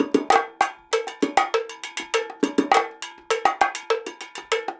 100 BONGO2.wav